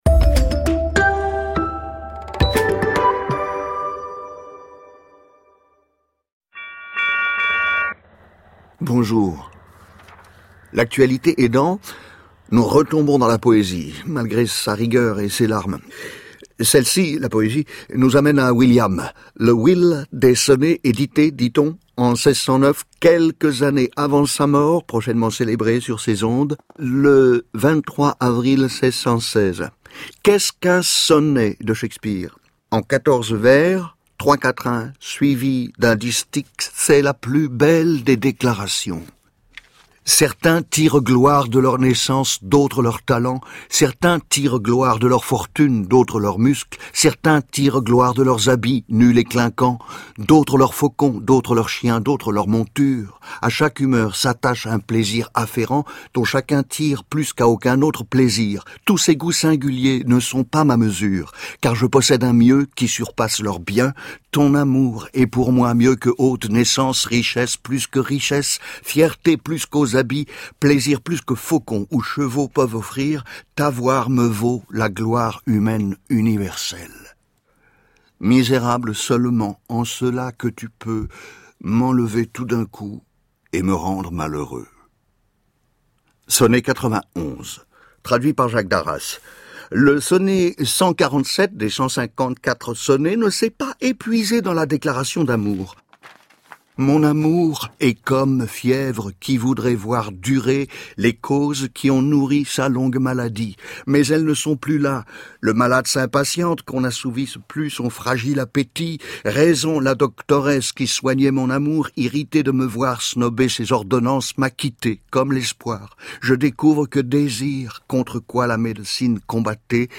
Parler sonnets par les sommets de Shakespeare (1/4) : La plus belle des déclarations (textes dits par Jacques Bonnaffé)
Jacques Bonnaffé dit la poésie sur France Culture :
Jacques Bonnaffé : acteur et metteur en scène